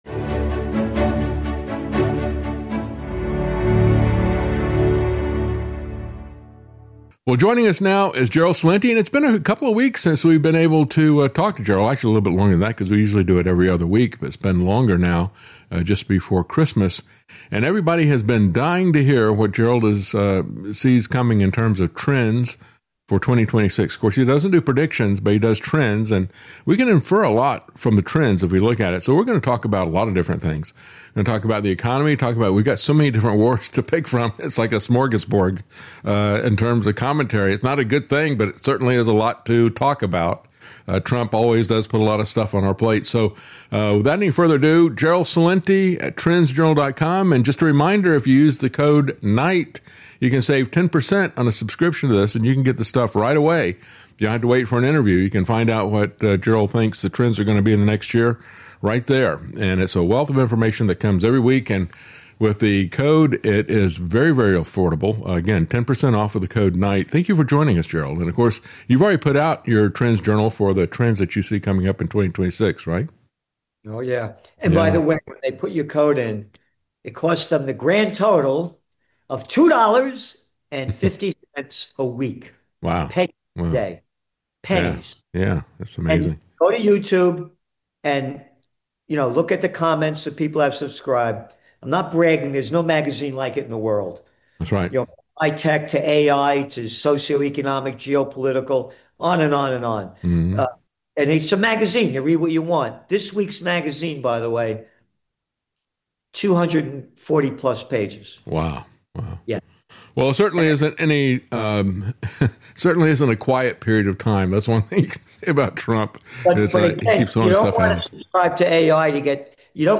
interview-this-is-what-system-failure-looks-like.mp3